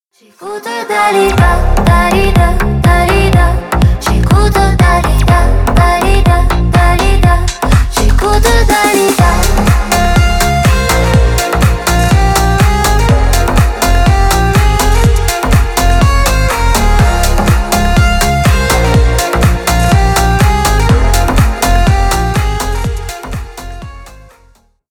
Поп Музыка # Танцевальные
клубные